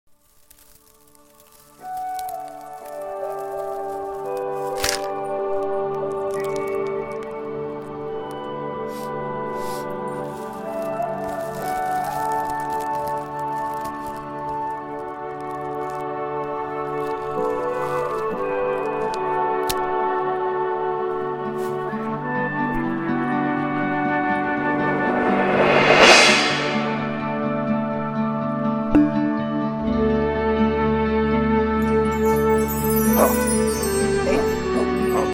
Sasquatch Sounds :) Creating live sound effects free download
Sasquatch Sounds :) Creating live sound effects for a scene from the new movie Sasquatch Sunset, one of the most unique and meditative movies I’ve ever seen!